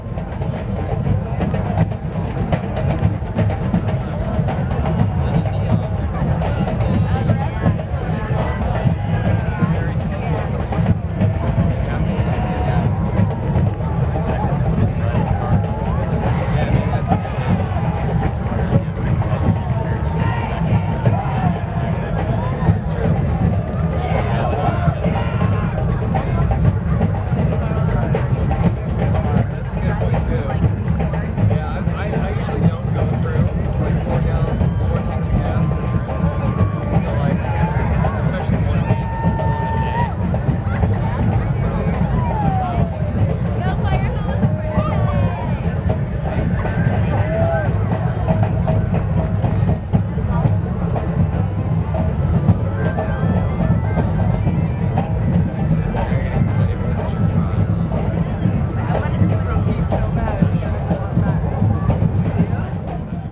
bm_drum_circle_people.L.wav